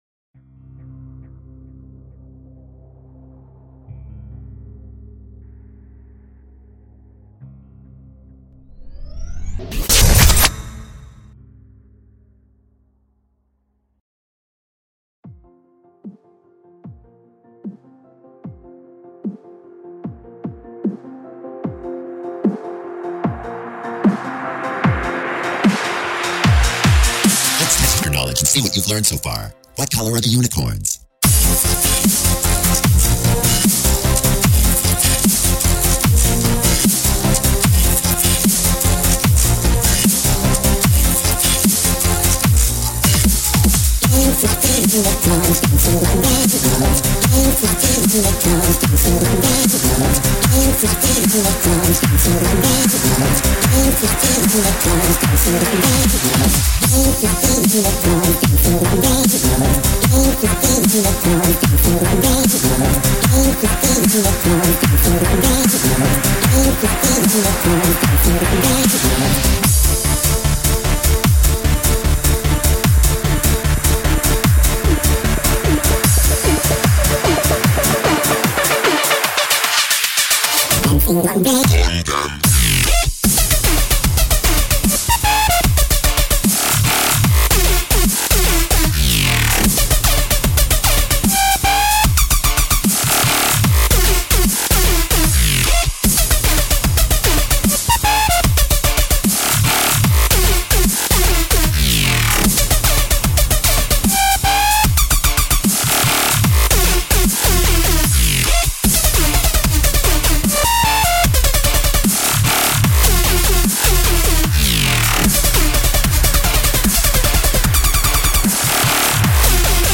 HEADPHONE WARNING